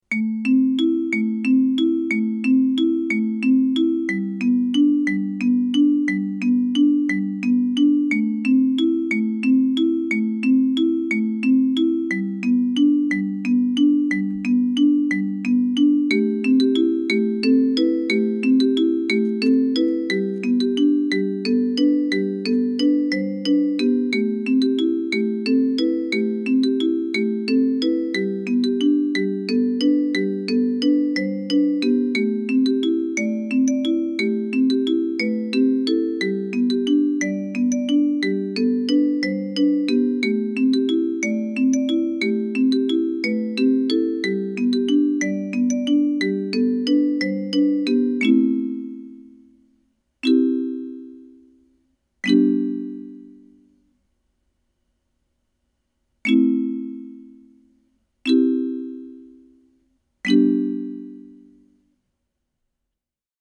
Alto Afroharp
The 13 notes of the classic 1970s Afroharp, mounted on a Hugh Tracey Alto kalimba.
The tuning was a hexatonic scale – it is like a pentatonic scale, but with an extra note.
It likes to play in E minor, or by tuning just the low E down a step to D, it plays beautifully in G major as well.
Afroharp_MP3_27.mp3